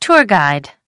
3. tour guide /tʊər ɡaɪd/: hướng dẫn viên du lịch